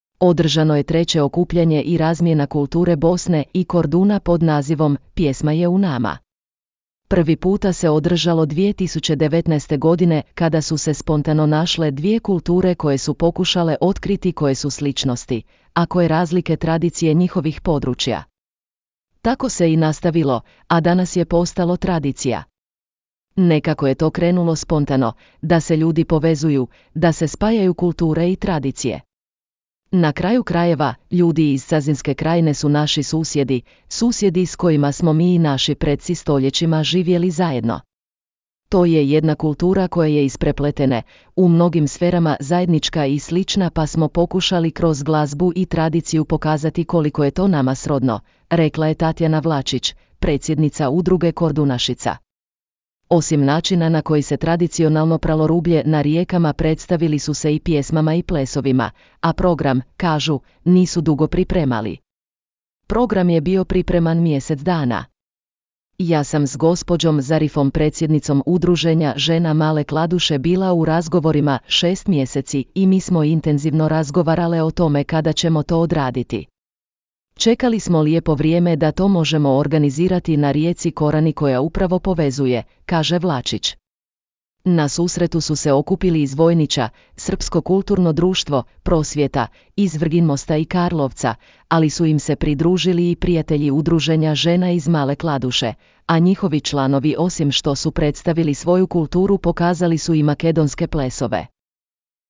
Održano je treće okupljanje i razmjena kulture Bosne i Korduna pod nazivom „Pjesma je u nama“.